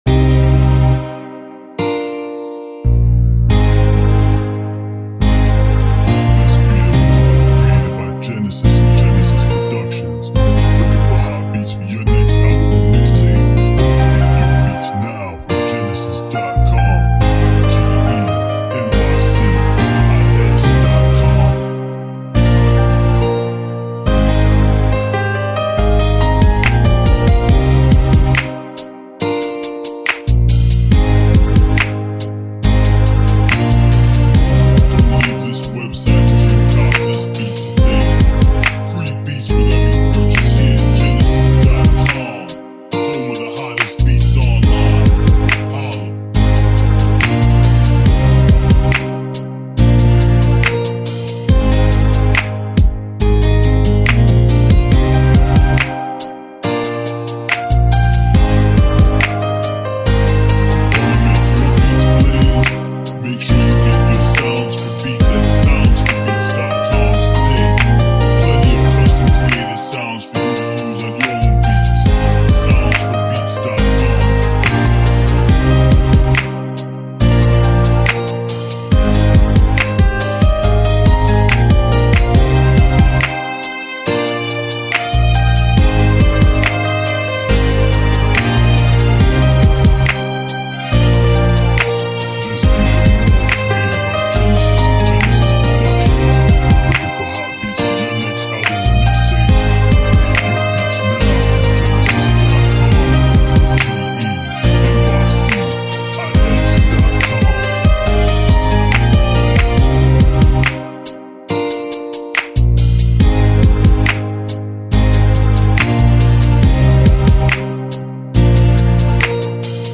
Seductive Guitar / Strings R&B Beat